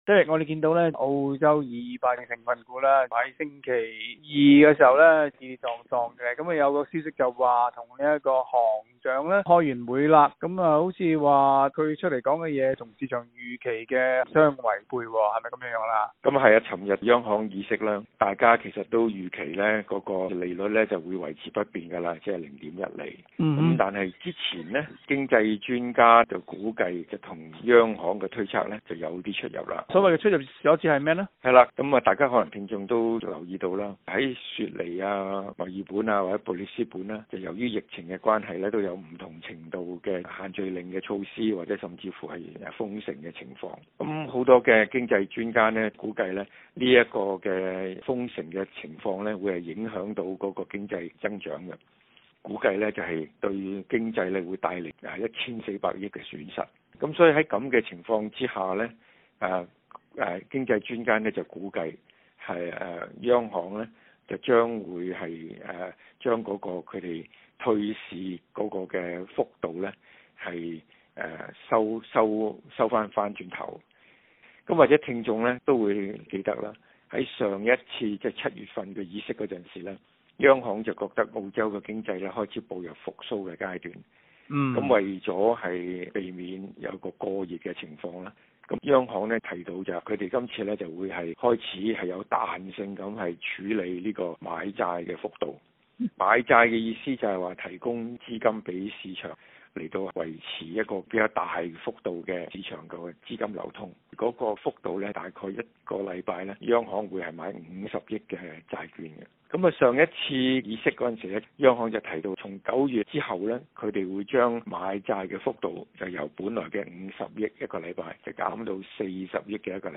詳情請收聽今日的訪問内容。